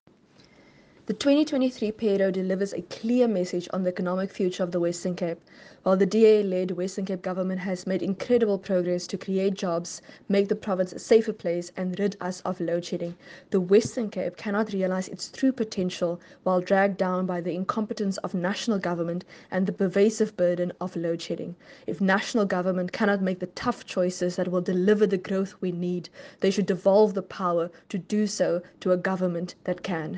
English audio clip from MPP Deidré Baartman attached.